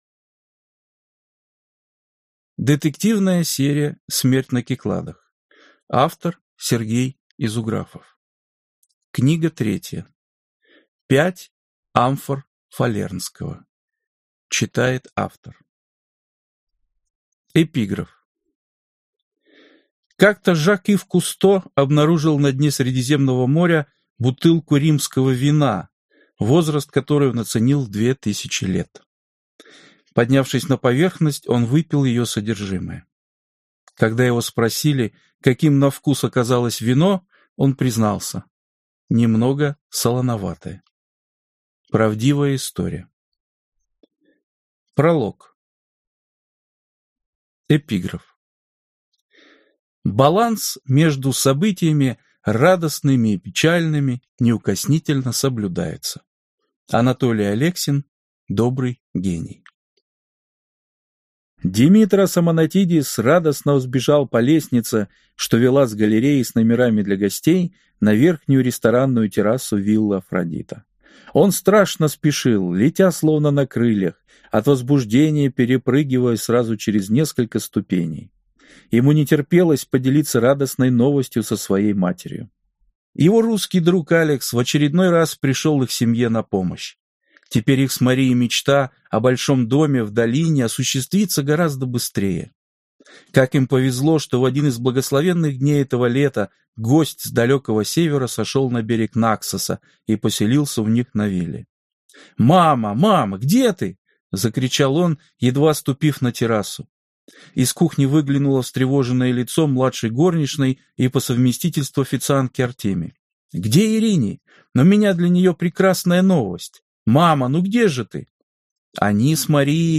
Аудиокнига Пять амфор фалернского | Библиотека аудиокниг